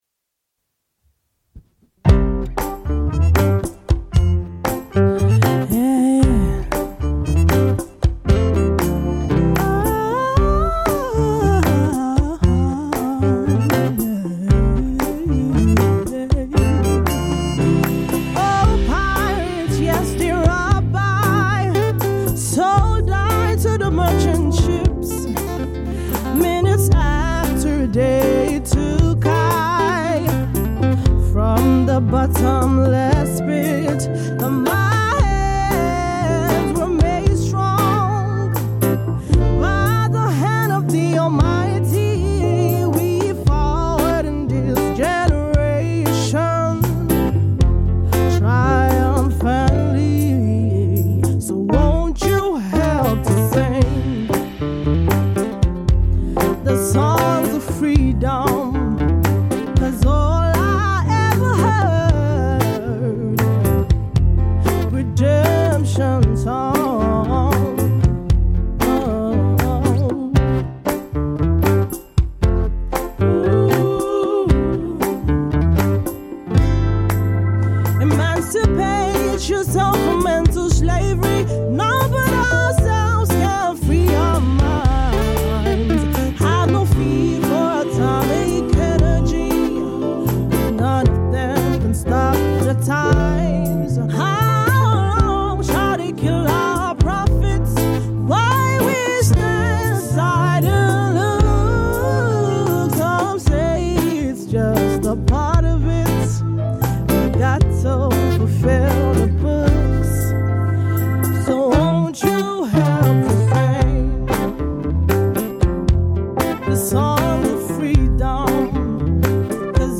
cover version
with a soulful twist